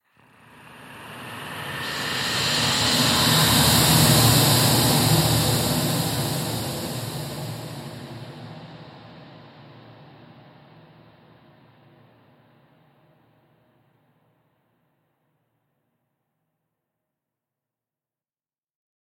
Звук жуткой атмосферы призраков
zvuk_zhutkoj_atmosferi_prizrakov_0q2.mp3